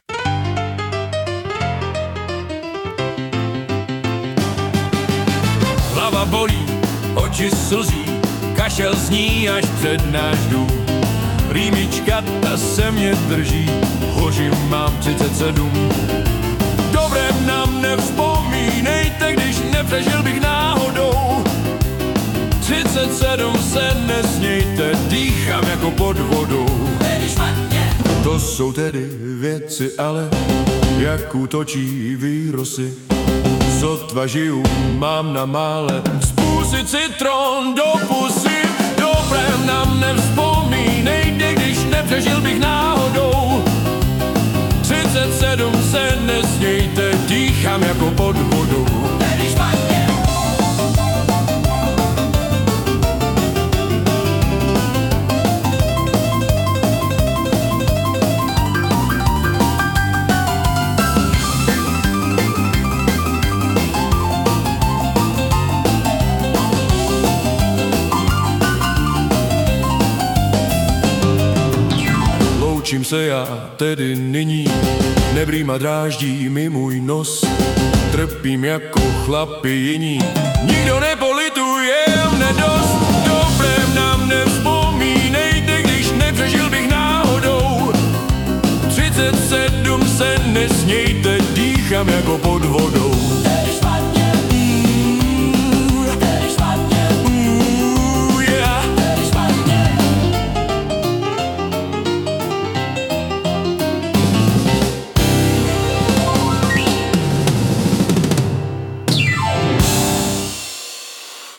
hudba, zpěv: AI
woogie boogie